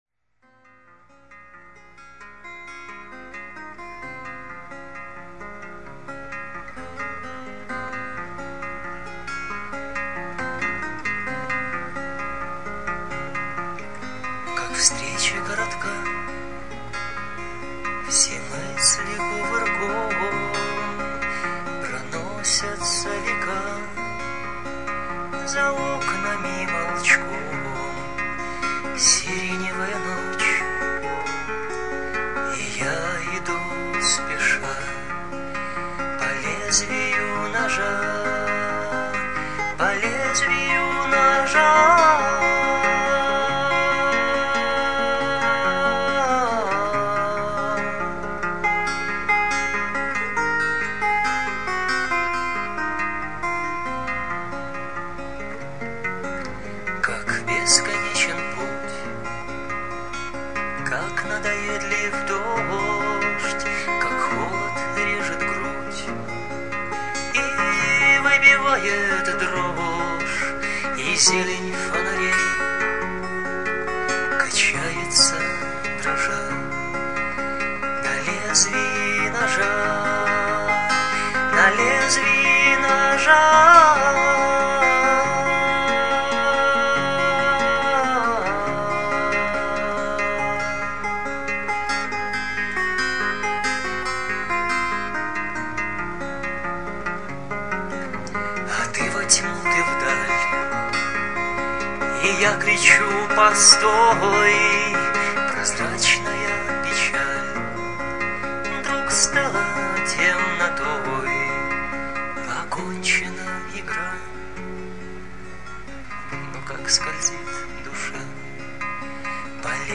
Мерзкого качества, срывающимся голосом, но ведь не это главное!?.
Рубрики:  Котозавывания